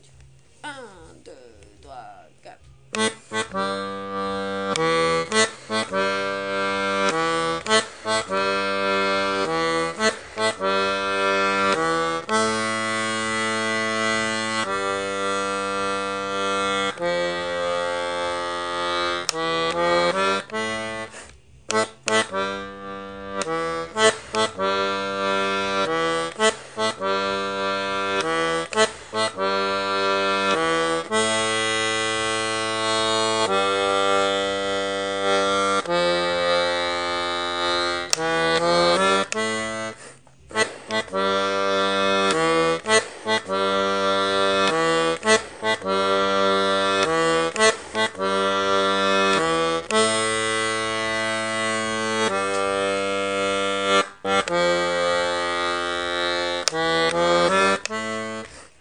l'atelier d'accordéon diatonique
accompagnement n°1 main gauche
je voudrais bien basses MG.mp3